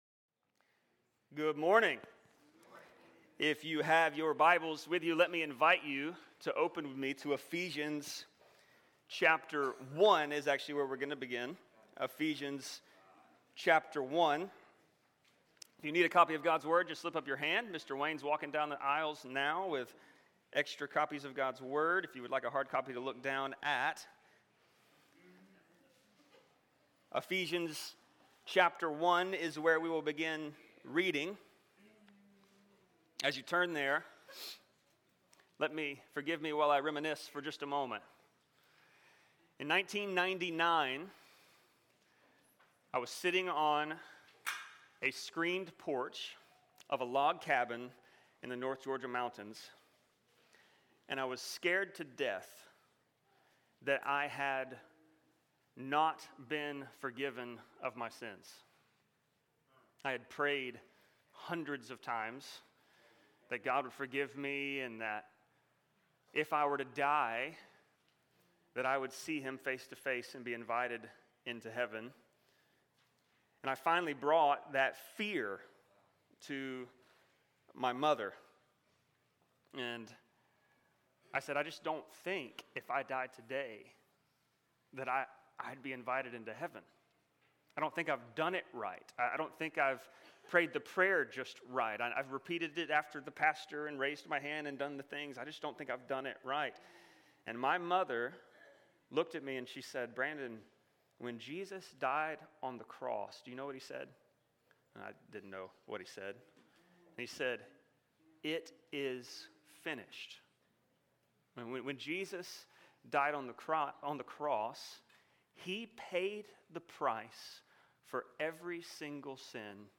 Sermons | St. Rose Community Church
Church Plant Sending Service